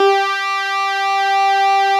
Added synth instrument
snes_synth_055.wav